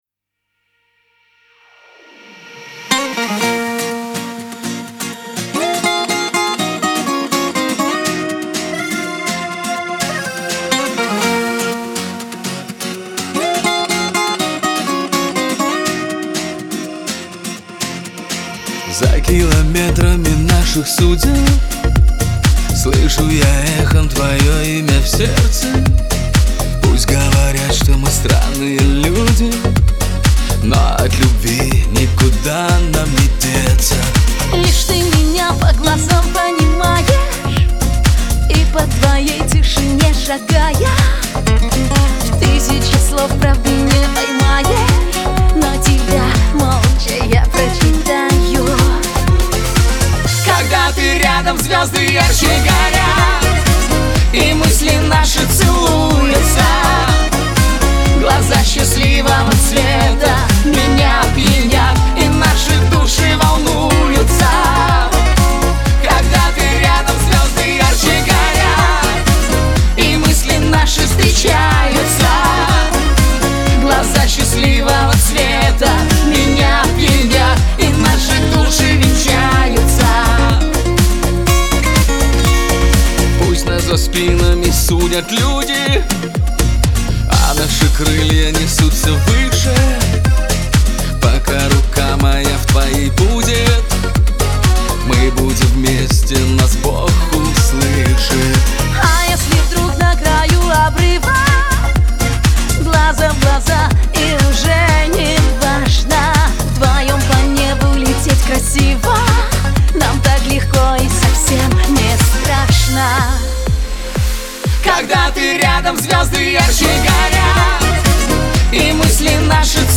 pop
Лирика , эстрада , дуэт
диско